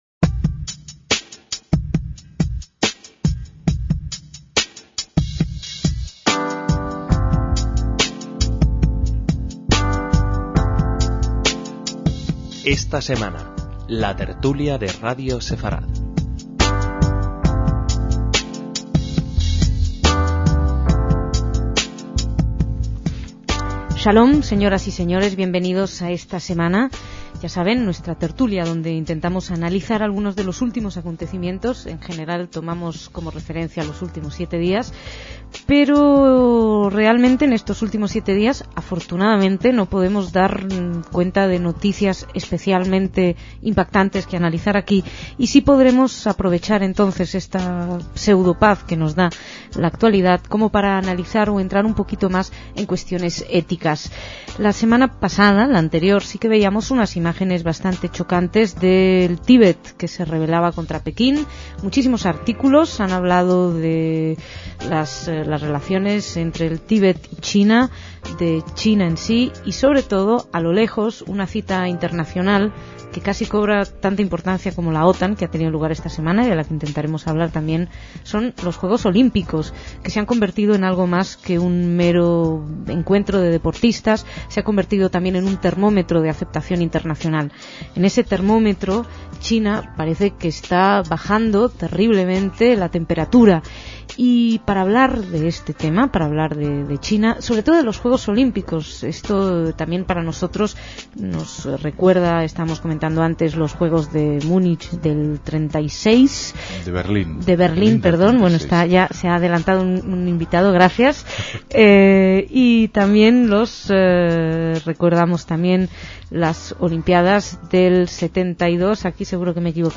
DECÍAMOS AYER (5/4/2008) - Los invitados a esta tertulia de abril de 2008